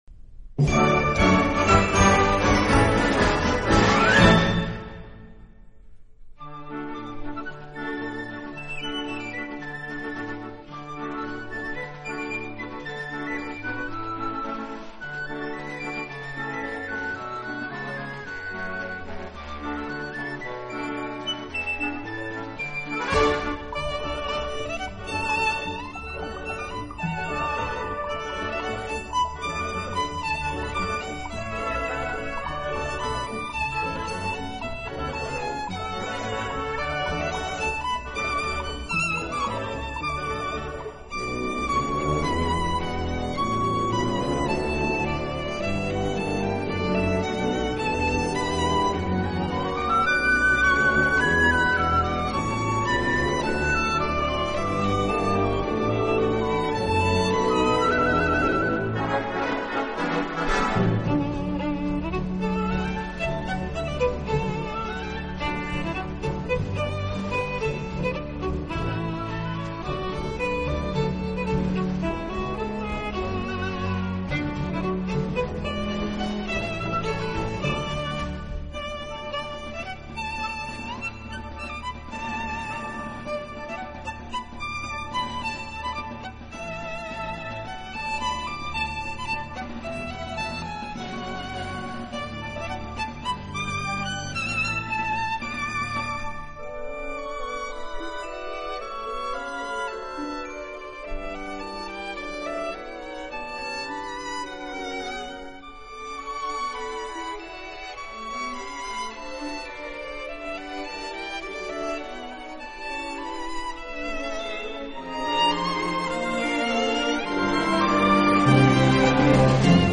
音乐类型：New  Age
引子，随后小提琴同样以跳跃的节奏演奏。伴随着气势磅礴的交响乐烘托，